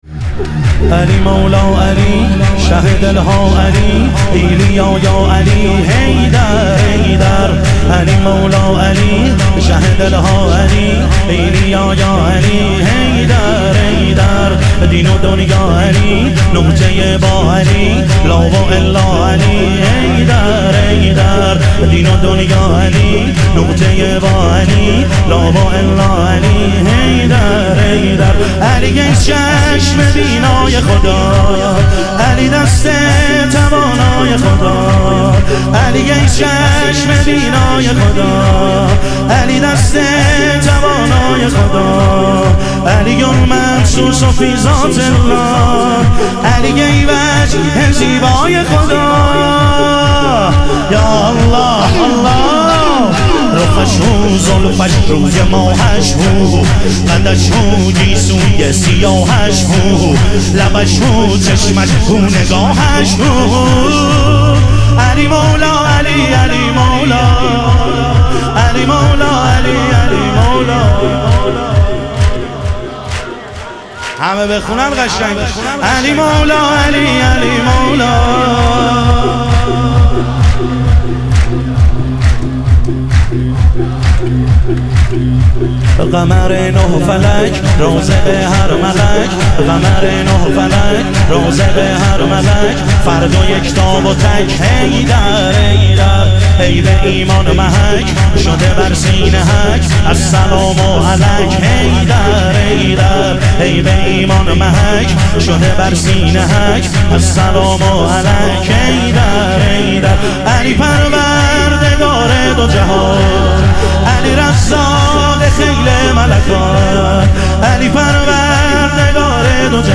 ظهور وجود مقدس حضرت امیرالمومنین علیه السلام - شور